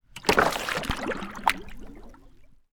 Water_30.wav